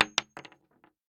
Bullet Shell Sounds
rifle_wood_6.ogg